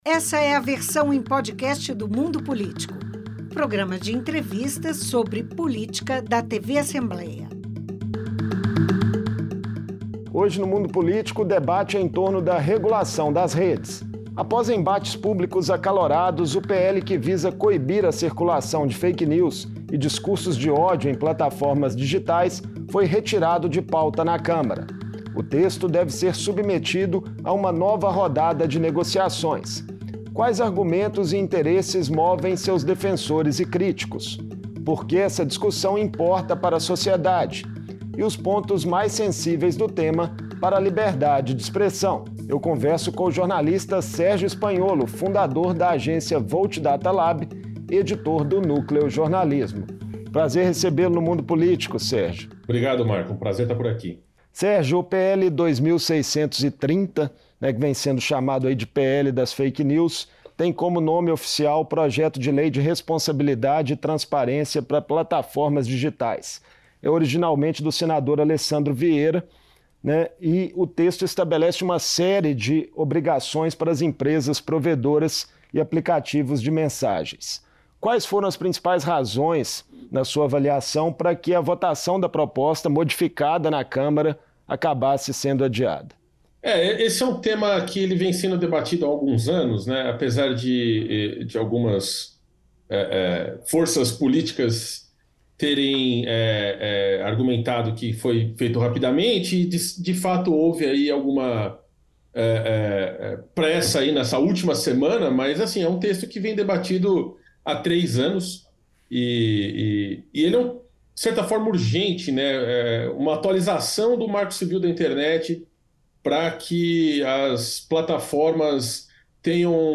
O projeto de lei de Responsabilidade e Transparência para Plataformas, mais conhecido como PL das Fake News não foi à votação após forte pressão das Big Techs e da oposição da extrema direita na Câmara. A regulação das plataformas é vista como necessária e urgente por diferentes grupos de interesse. Em entrevista